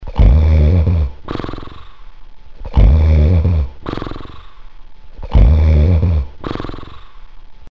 Snore Sound Effect Free Download
Snore